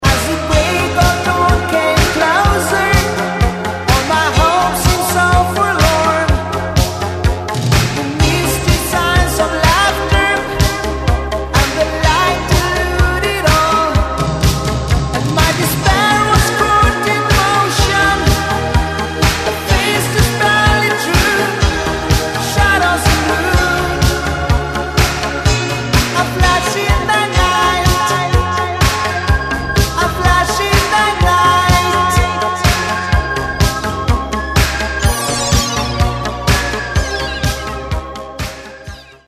80, 90, Рэтро